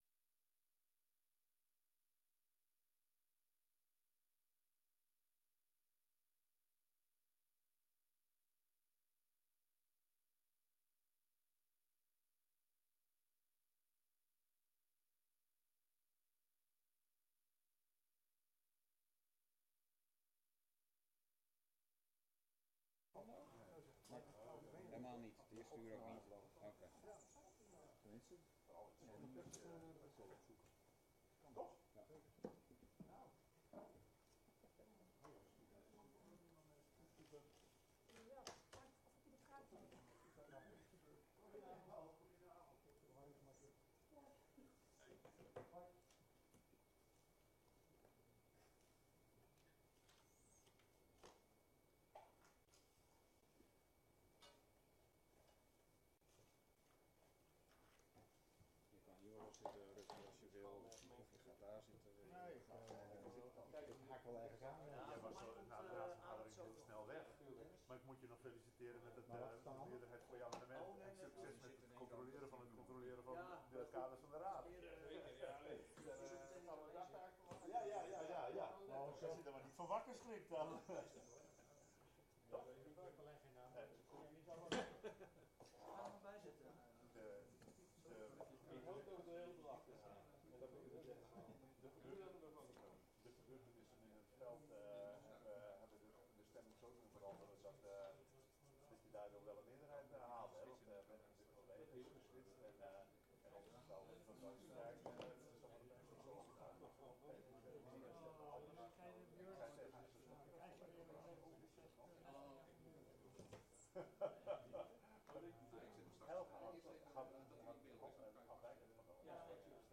Themabijeenkomst 06 februari 2025 19:30:00, Gemeente Noordoostpolder
Locatie: Raadzaal